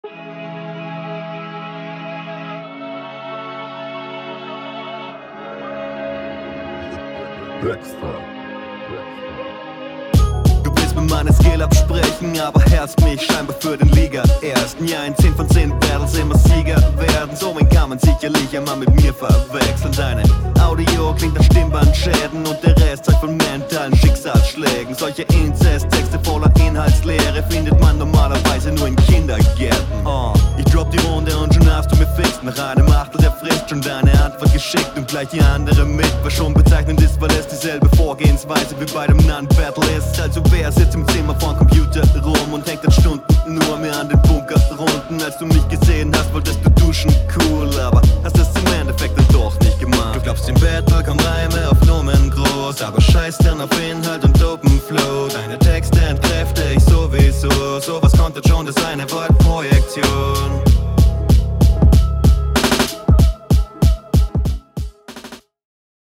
Etwas leise gemixt, aber trotzdem noch viel angenehmer als beim Gegner.